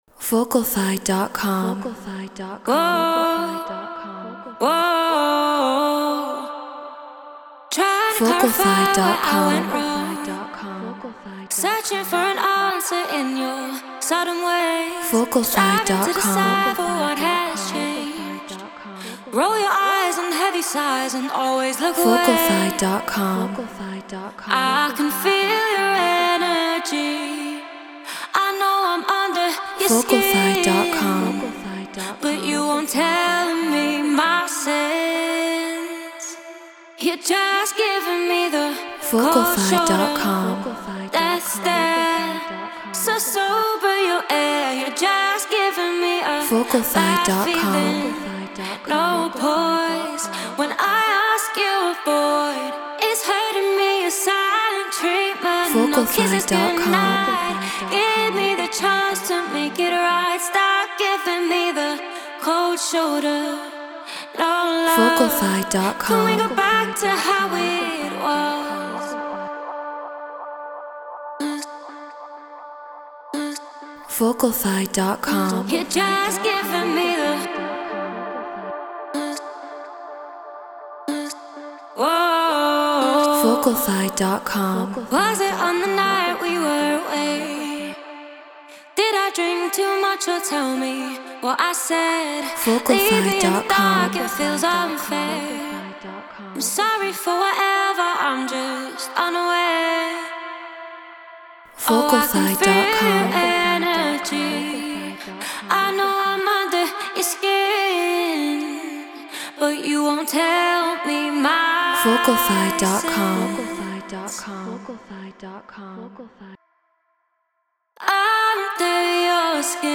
House 124 BPM Dmin